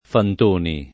[ fant 1 ni ]